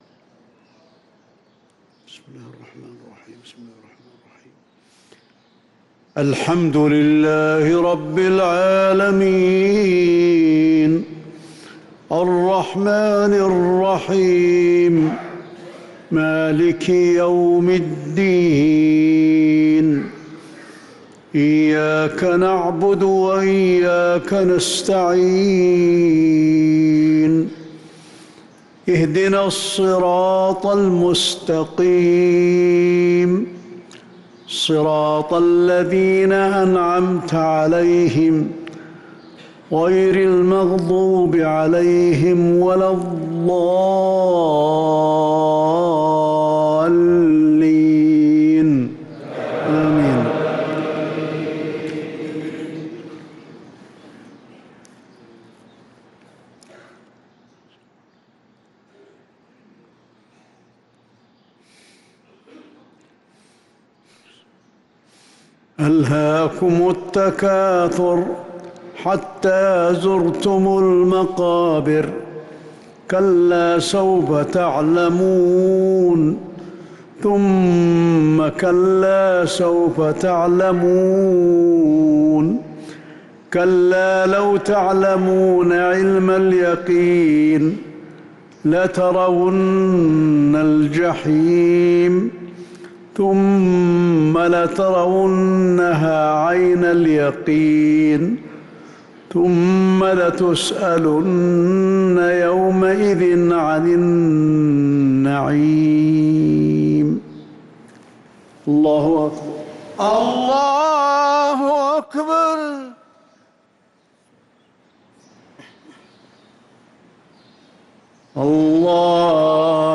صلاة المغرب للقارئ علي الحذيفي 25 جمادي الآخر 1445 هـ
تِلَاوَات الْحَرَمَيْن .